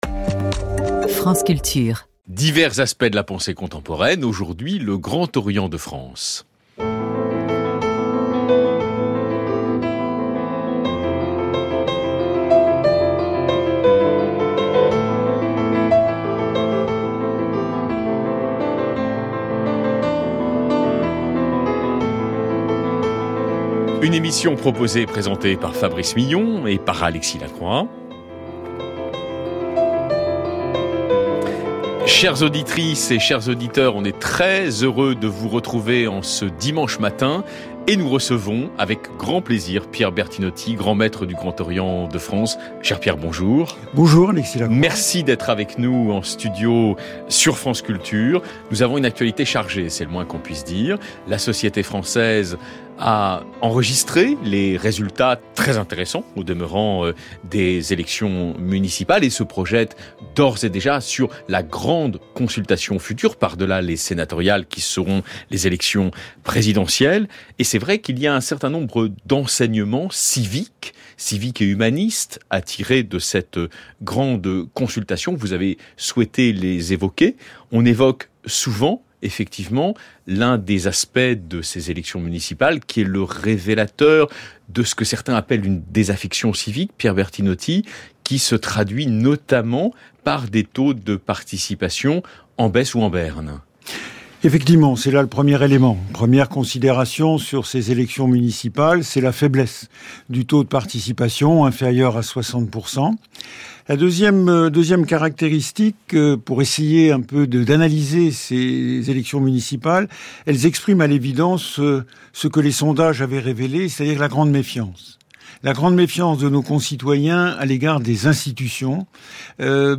Conférence publique organisée par le Grand Orient de France, dans le cadre des Chantiers de la République, jeudi 27 février 2025 à Paris.